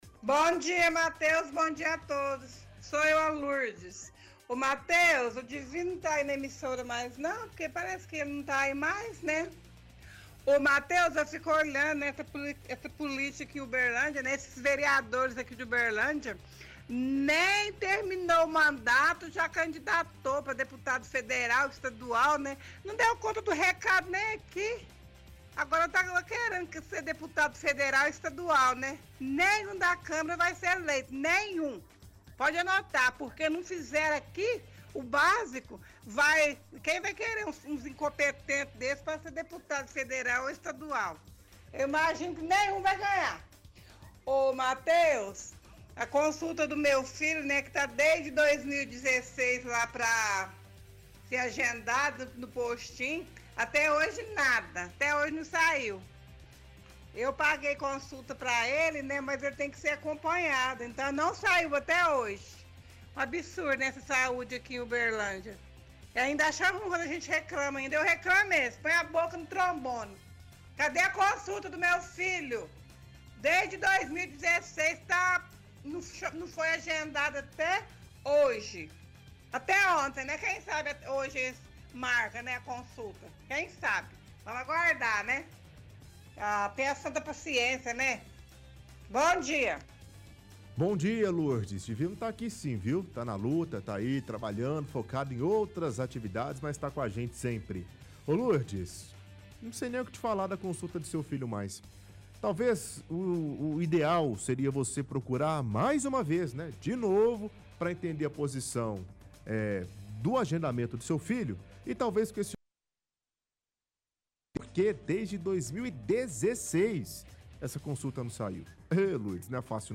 – Ouvinte defende que nenhum dos vereadores que se candidataram a deputado vão ganhar, pois não fizeram direito o trabalho na cidade.